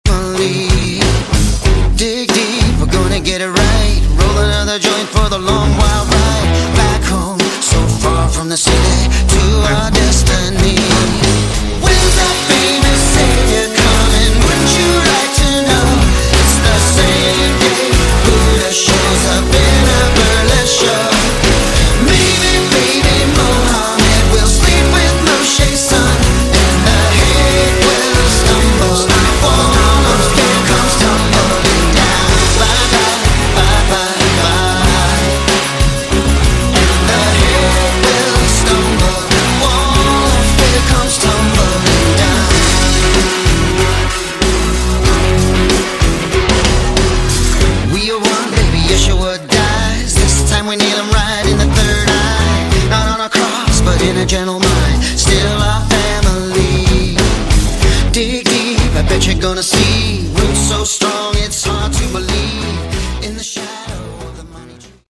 Category: Melodic Rock
lead vocals, guitars, piano
drums, percussion, electronics
keyboards
electric and acoustic bass, vocals